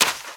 STEPS Sand, Run 26.wav